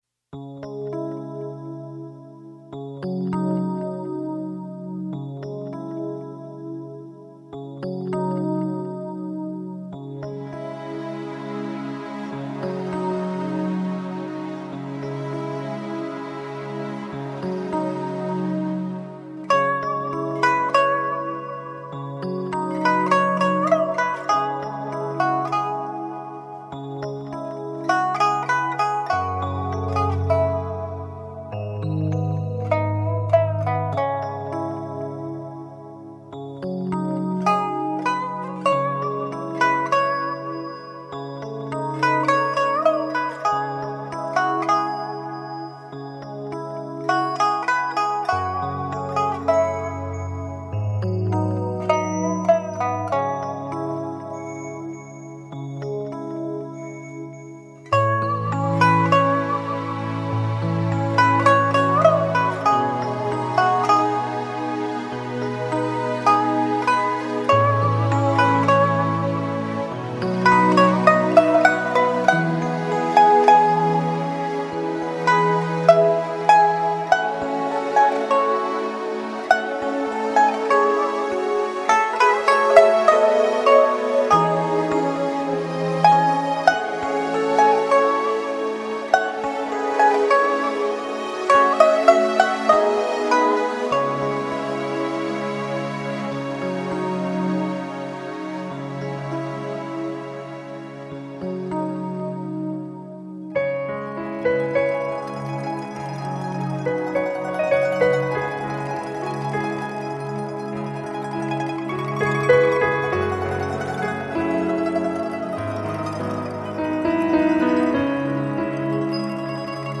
浪漫情调，清新华丽，迷人诗意伴你一路抒情。
琵琶